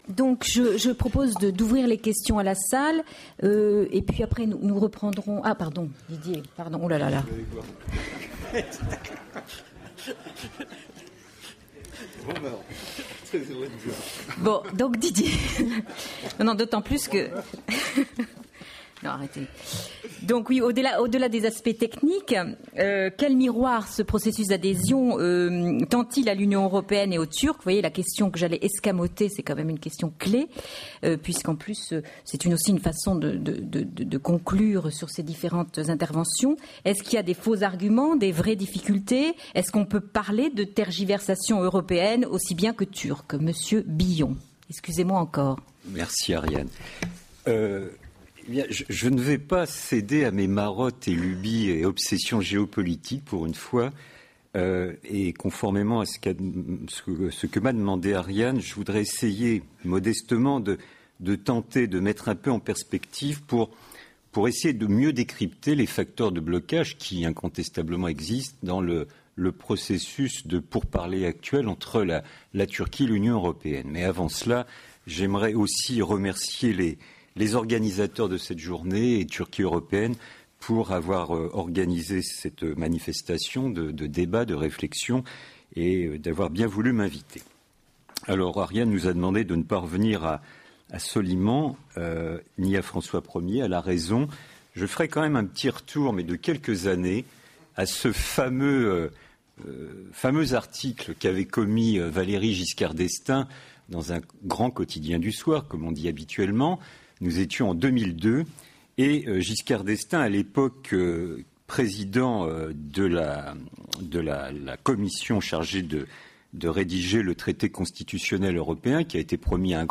Le 24 octobre 2009 Turquie Européenne a organisé dans le cadre de la Saison de la Turquie en France, une conférence-débat :